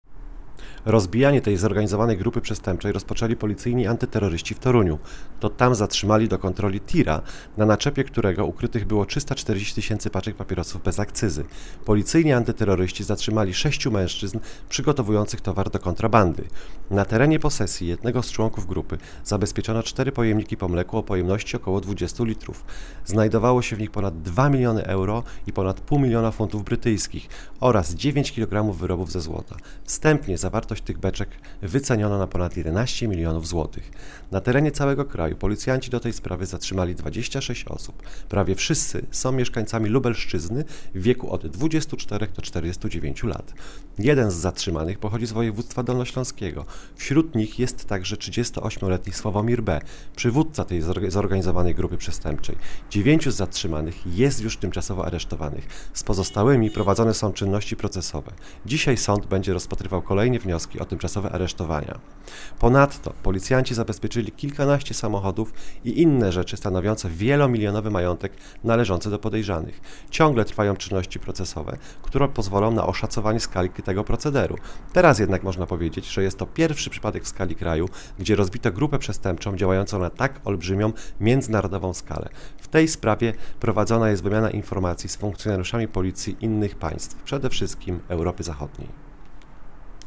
Nagranie audio Mówi nadkomisarz